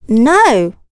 Ophelia-vox-Deny2.wav